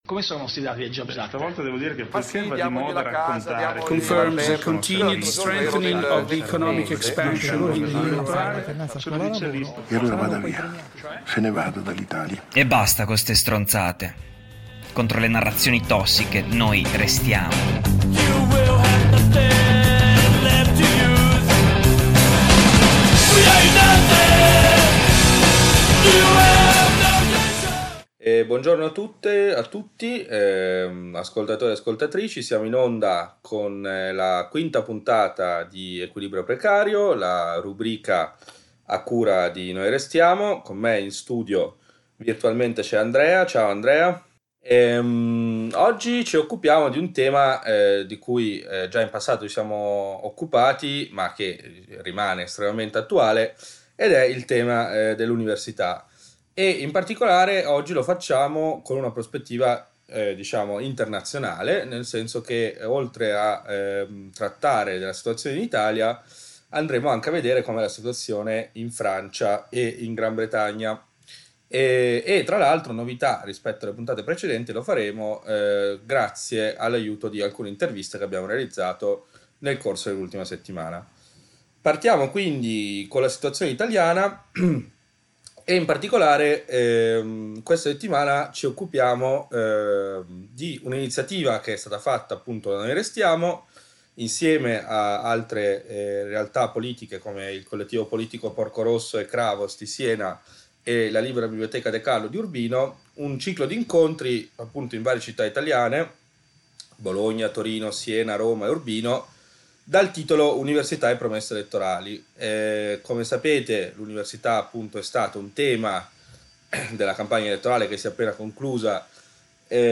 Equilibrio Precario Puntata 5 ASCOLTA L'INTERVISTA